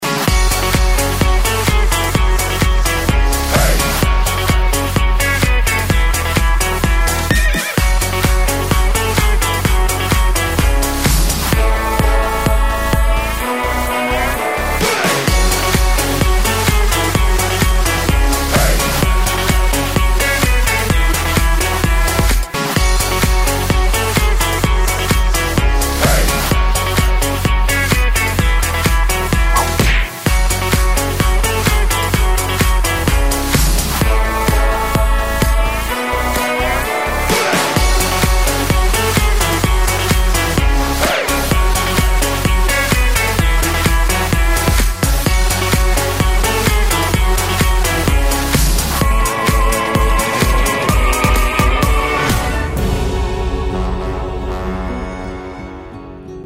• Качество: 128, Stereo
красивые
без слов
инструментальные
ковбойские